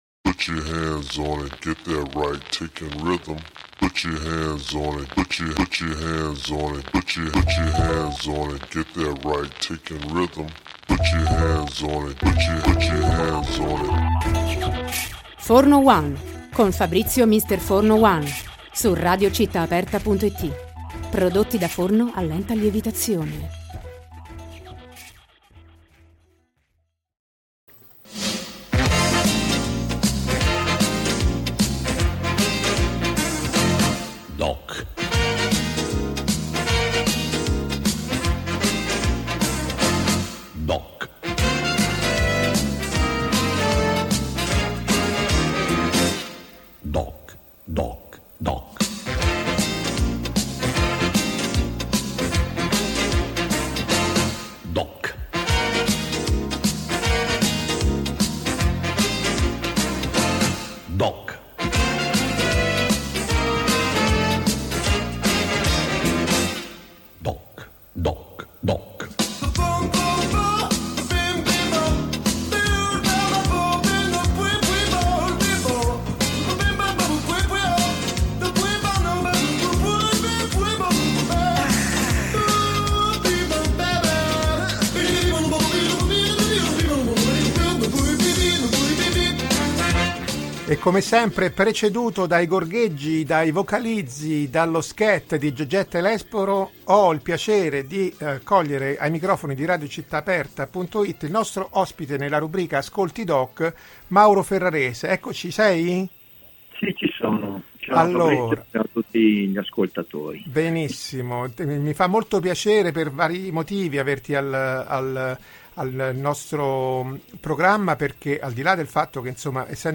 Come da formula collaudata della rubrica, l’ospite ci ha portato un suo brano del cuore spiegando ai nostri microfoni il motivo di tale scelta.